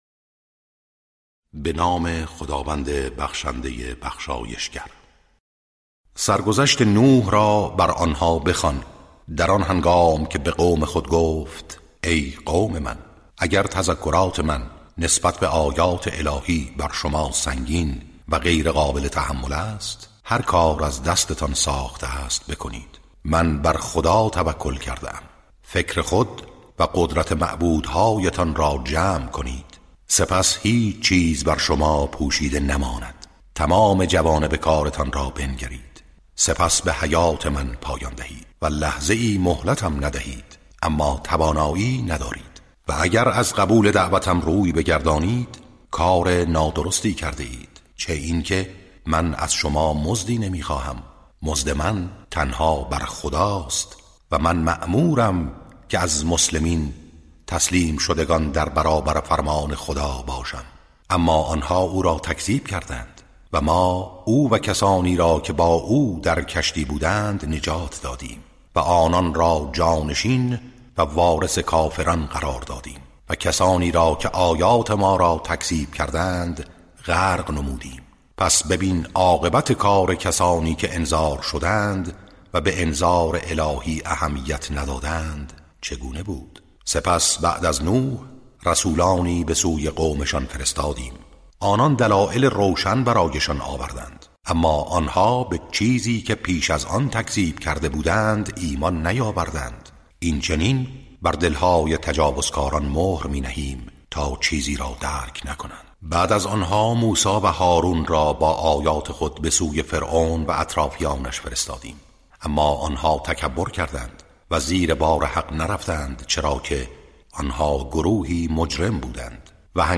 ترتیل صفحه ۲۱۷ سوره مبارکه یونس(جزء یازدهم)